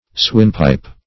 swinepipe - definition of swinepipe - synonyms, pronunciation, spelling from Free Dictionary Search Result for " swinepipe" : The Collaborative International Dictionary of English v.0.48: Swinepipe \Swine"pipe`\, n. (Zool.) The European redwing.